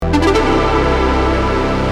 Помогите накрутить athmo pluck